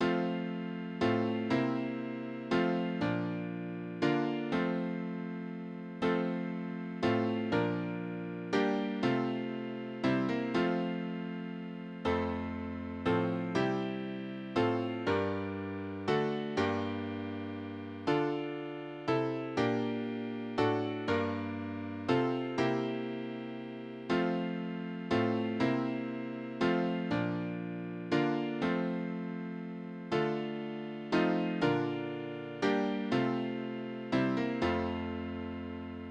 Geistliche Lieder: Marterbild
Tonart: F-Dur
Taktart: 3/4
Tonumfang: kleine Septime